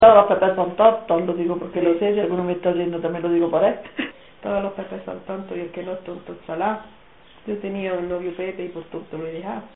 Secciones - Biblioteca de Voces - Cultura oral